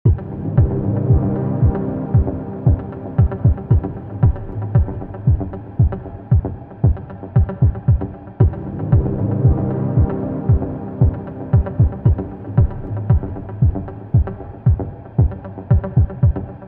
short dub techno loop